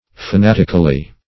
-Fa*nat"ic*al*ly, adv. -- Fa*nat"ic*al*ness, n.